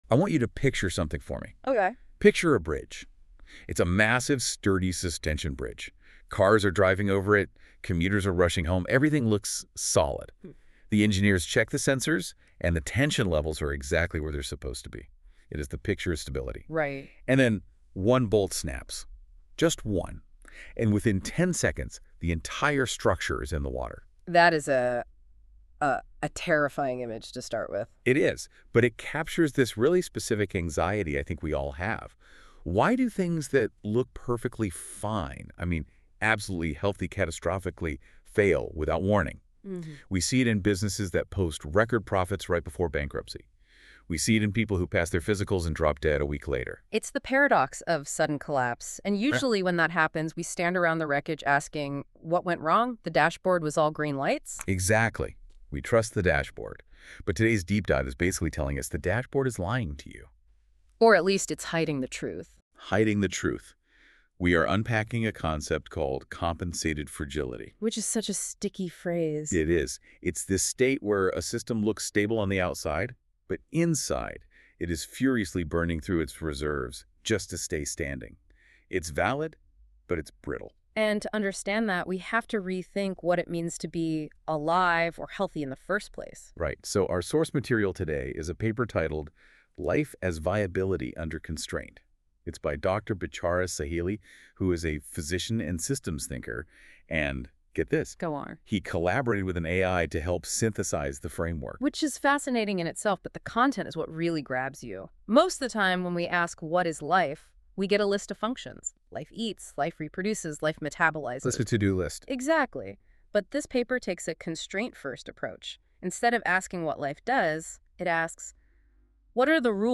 Deep Dive Audio Overview